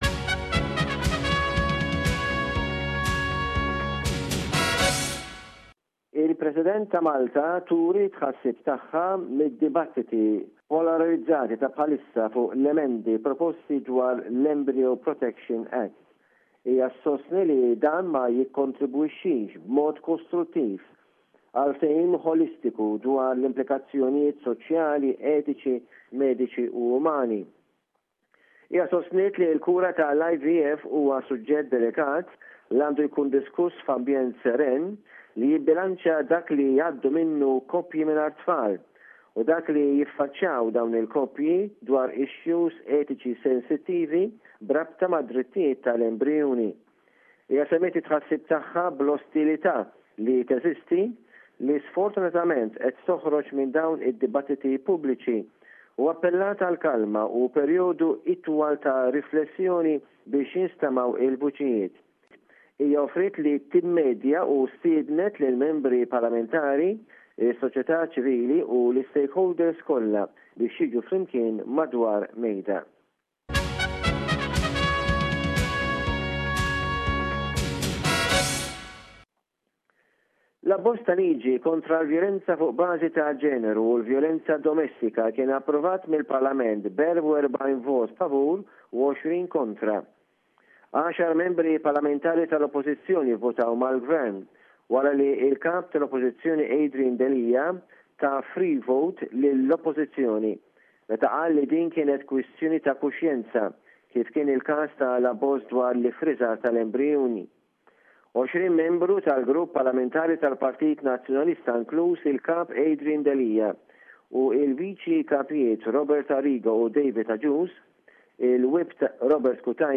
SBS Maltese